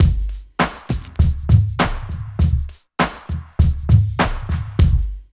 88 DRUM LP-L.wav